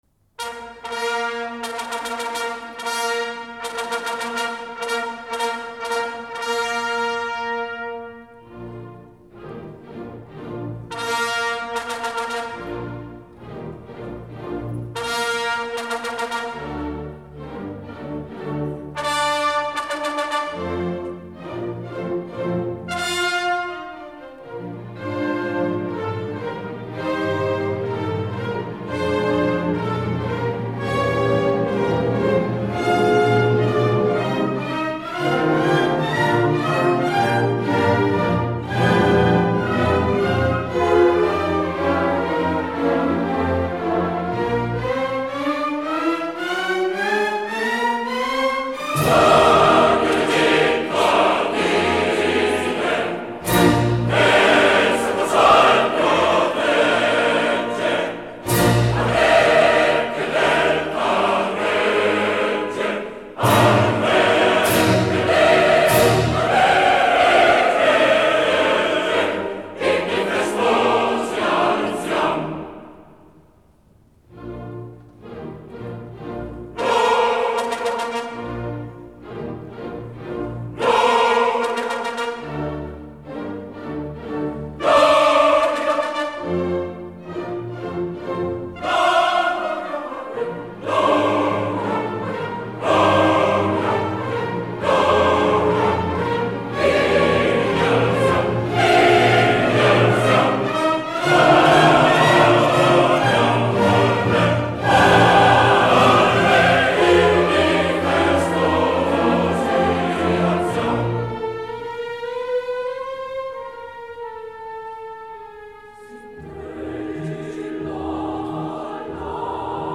Запись 3-16 IX. 1955, театр "Ла Скала", Милан.